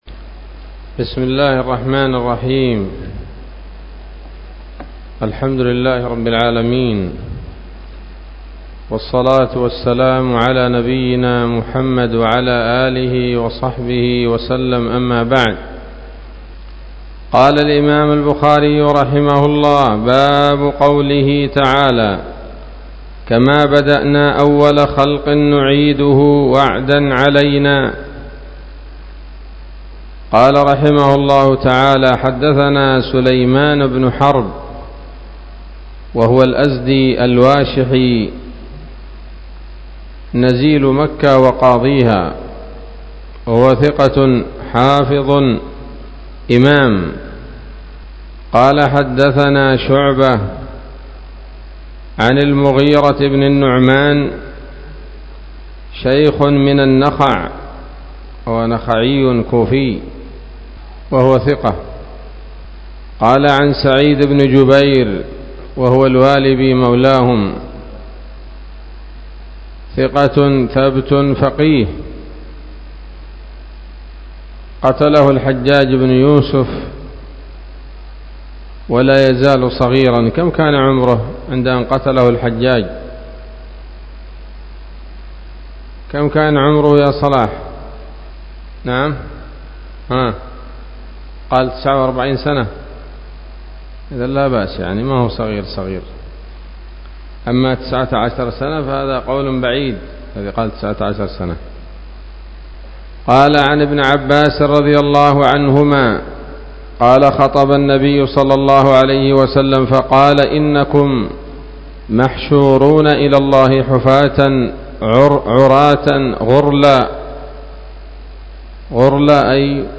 الدرس الرابع والسبعون بعد المائة من كتاب التفسير من صحيح الإمام البخاري